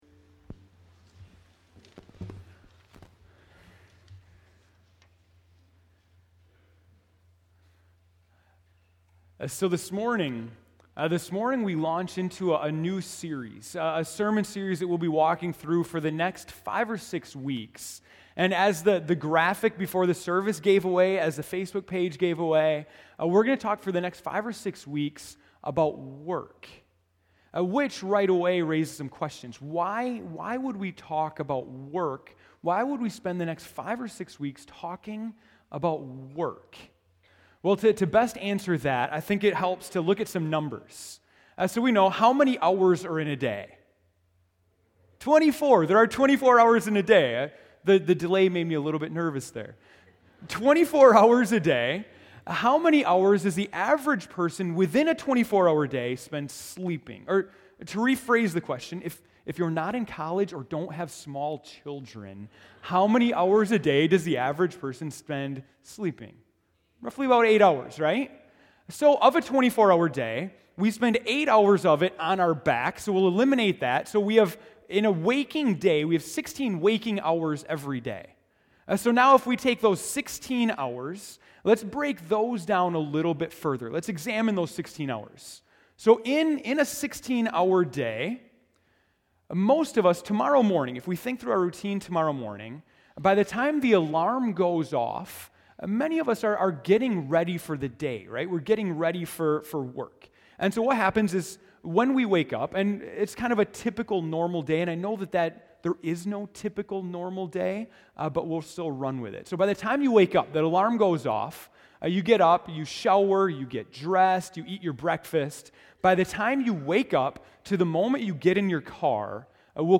January 19, 2014 (Morning Worship)